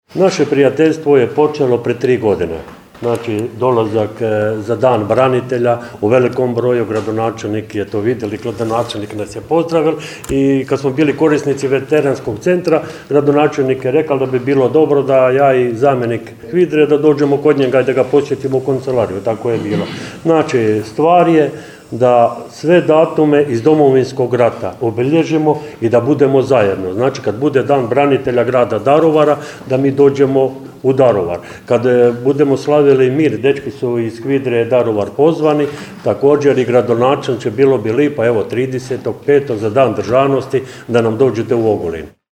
Povodom obilježavanja 30. obljetnice vojno-redarstvene akcije Bljesak u Velikoj vijećnici zgrade Gradske uprave organizirano je  potpisivanje Povelje o prijateljstvu i suradnji između Udruge HVIDR-a Daruvar i Udruge  HVIDR-a Ogulin.